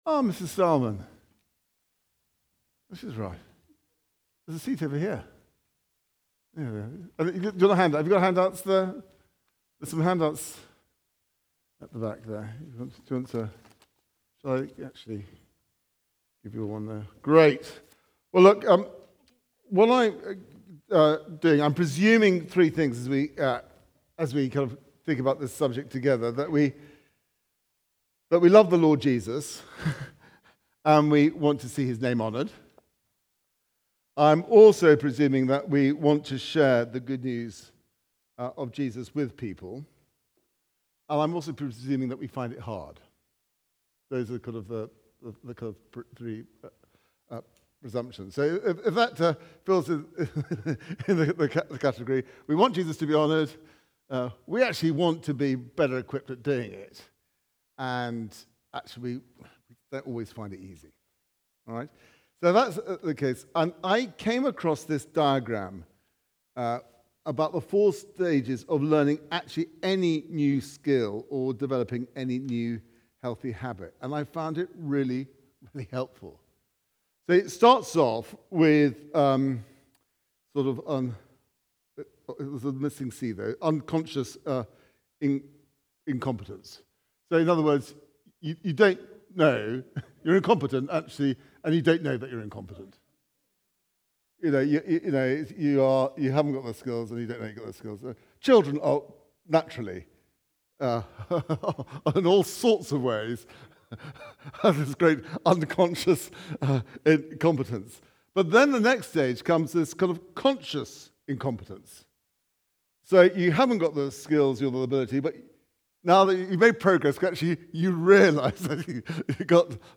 Confident Evangelism - Part 1 from the series Growing in Confident Evangelism. Recorded at Woodstock Road Baptist Church on 22 February 2026.